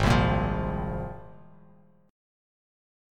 G#6add9 chord